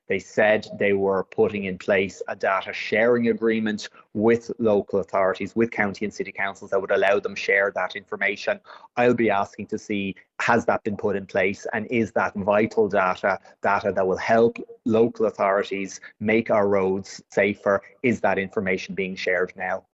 Oireachtas Transport Committee member Roderic O’Gorman says this should be a no-brainer: